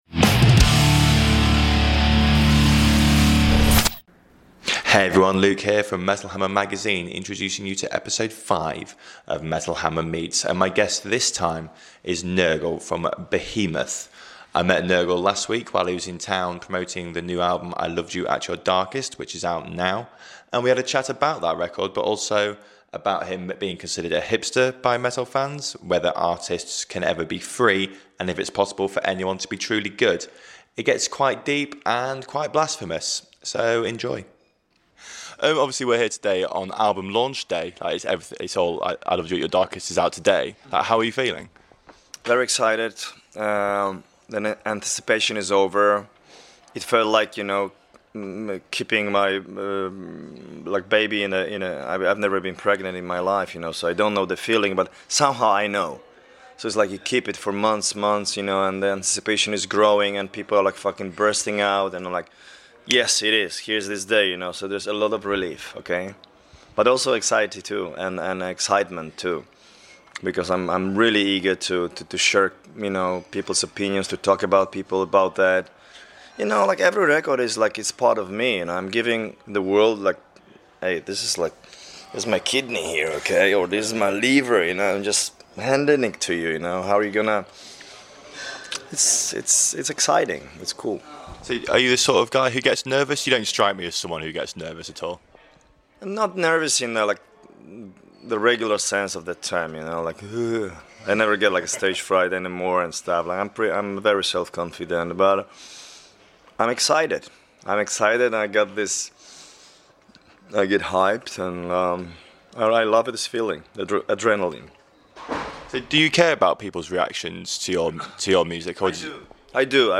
It's a chat full of philosophy and blasphemy.